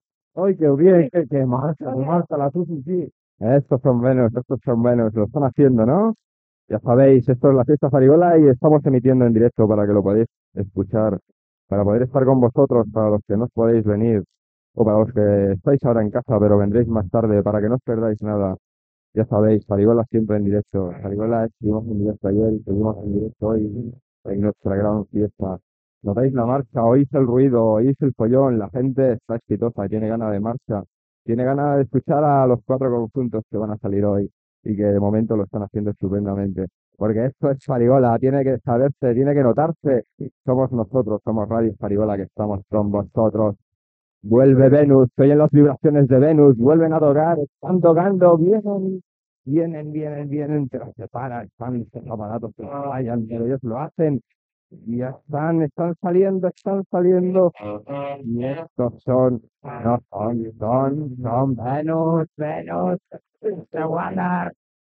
Transmissió de la Festa de Ràdio Farigola a Nou Barris on actúa el grup Venus, amb esment al concert fet a Barcelona per Jethro Tull.